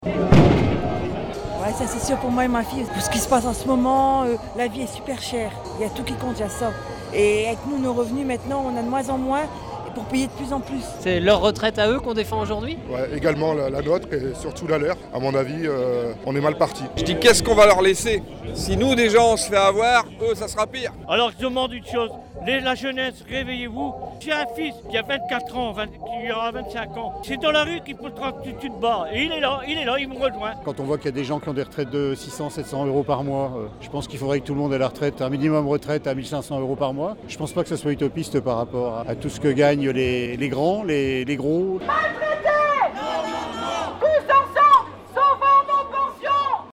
Voilà ce que l’on pouvait entendre hier soir dans les rues de la Cité Jean Bart lors du passage du cortège de syndicats contre le projet de réforme des retraites.